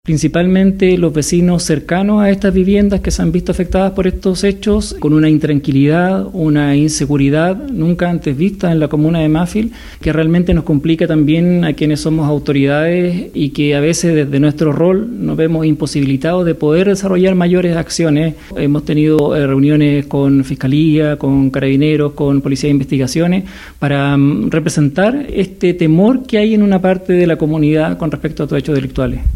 Al ser consultado por la sensación de seguridad de sus vecinos, Lara reconoció que un grupo de la población siente temor.